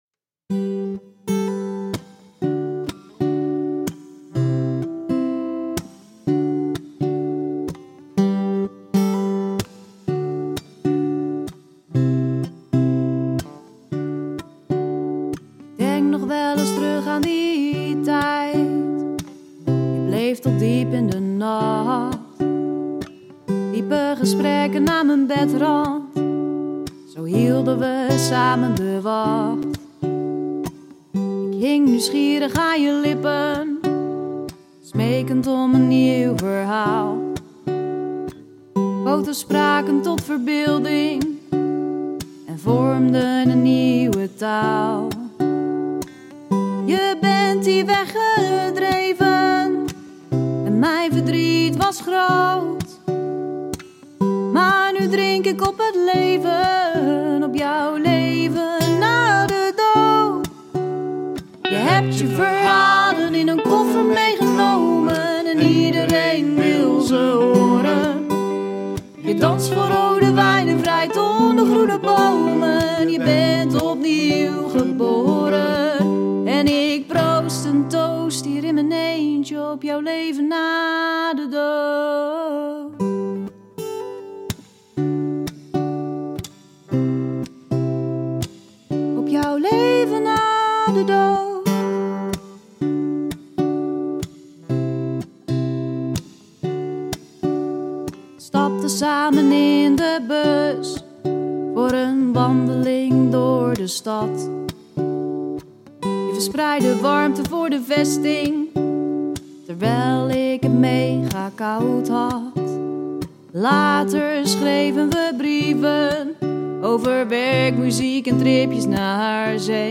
Puur, twee gitaren en twee zangstemmen.